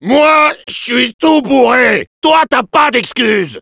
Le Demoman parlant des OVNIS transporteurs
Demoman_jeers03_fr.wav